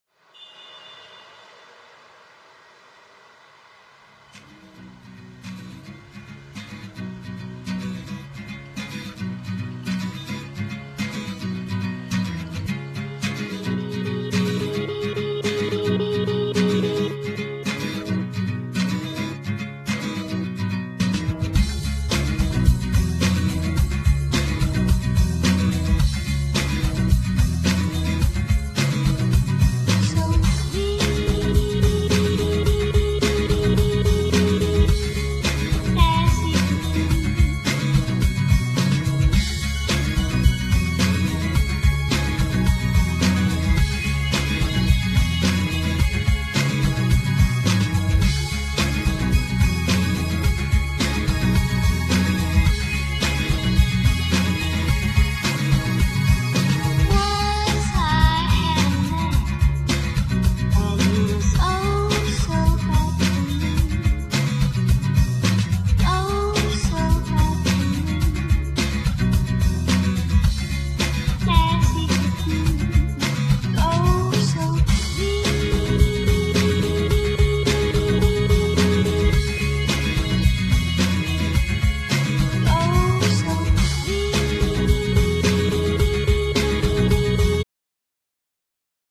Genere : pop electronico